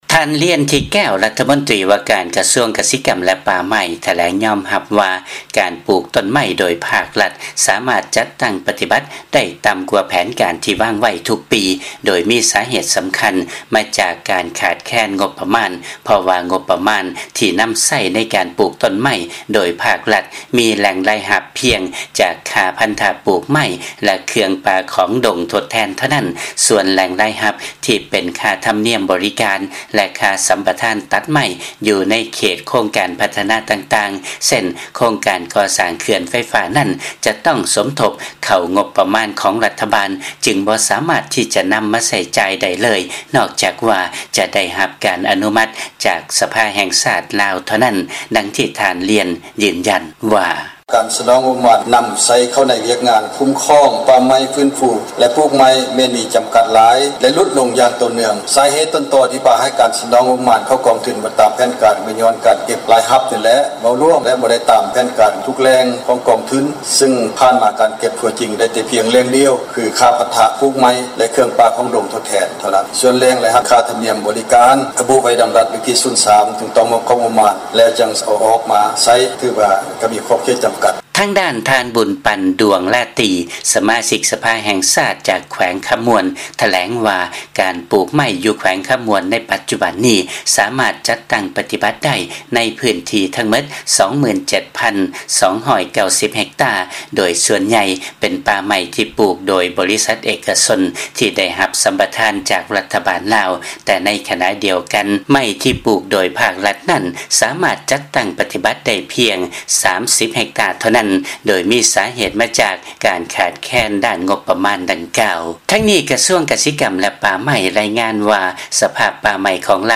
ຟັງລາຍງານ ປ່າໄມ້ໃນລາວ ສ່ວນໃຫຍ່ ປູກໂດຍເອກະຊົນ, ສ່ວນລັດໄດ້ປູກ ພຽງເລັກນ້ອຍ ຍ້ອນຂາດງົບປະມານ